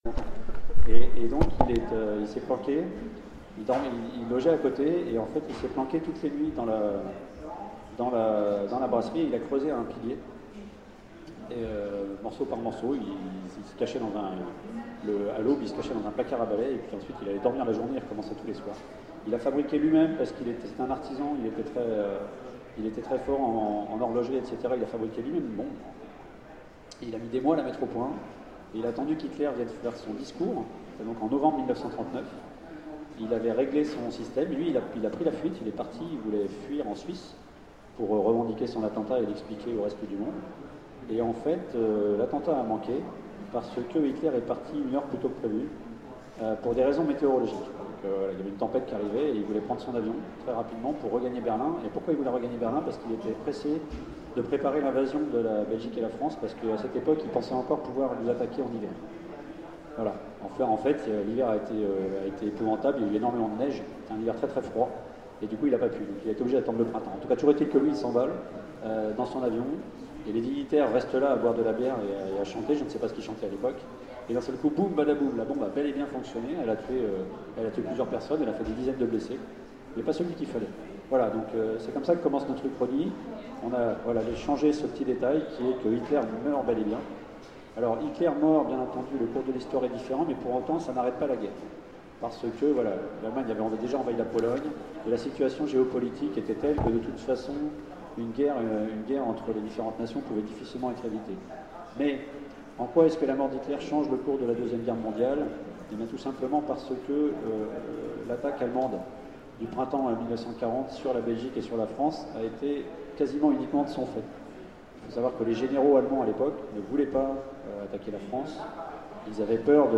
Utopiales 13 : Conférence
Conférence
Mots-clés Rencontre avec un auteur Conférence Partager cet article